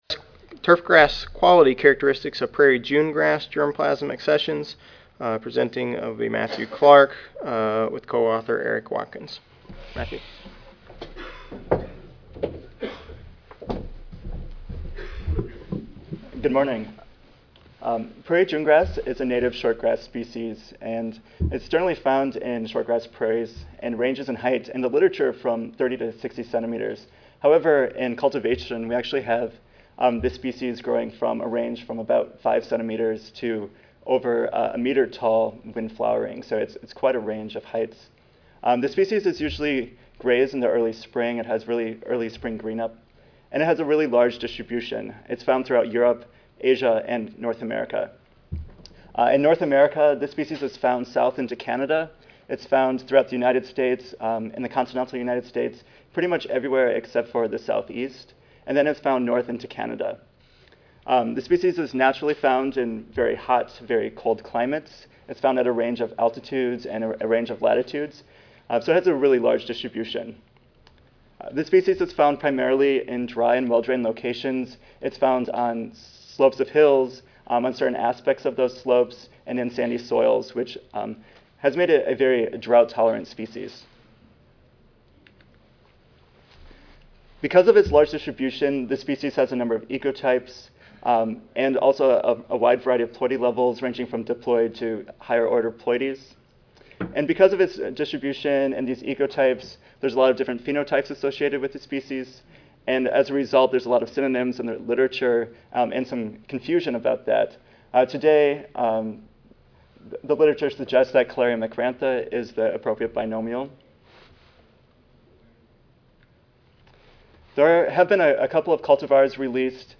See more from this Division: C05 Turfgrass Science See more from this Session: Graduate Student Oral Competition: I